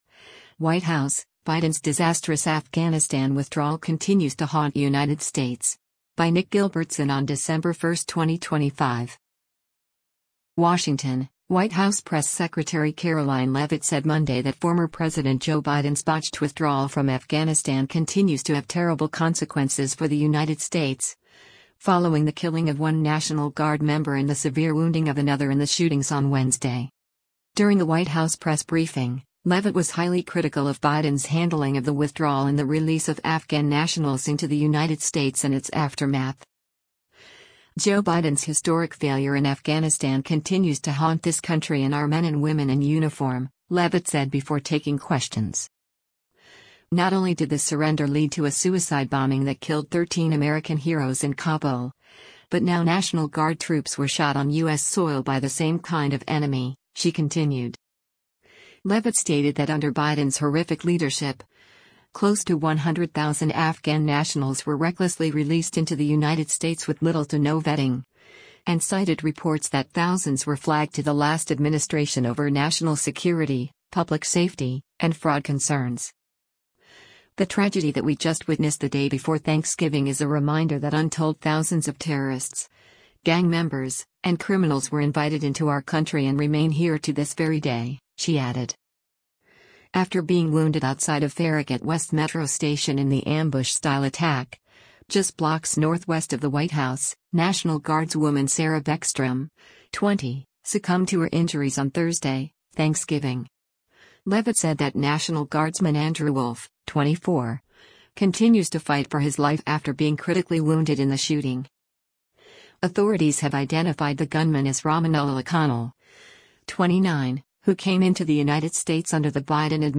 During a White House press briefing, Leavitt was highly critical of Biden’s handling of the withdrawal and the release of Afghan nationals into the United States in its aftermath.